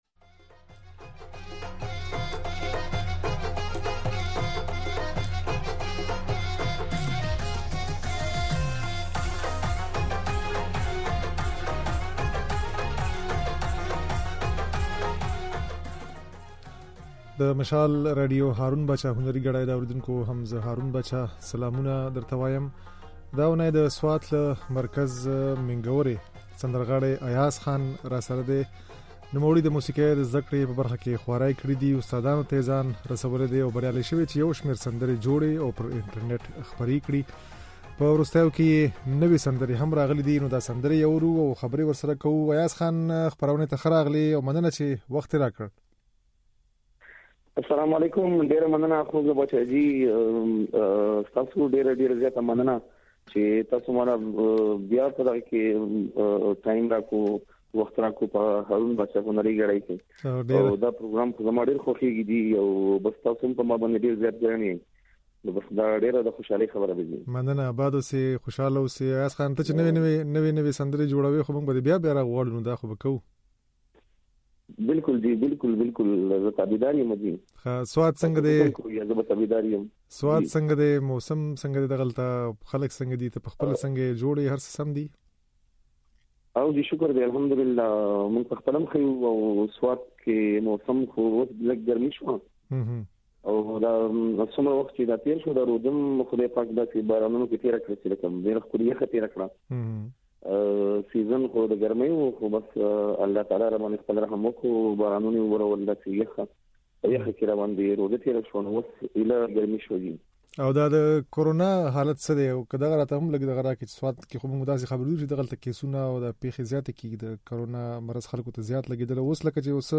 د هغه ځينې سندرې اورو.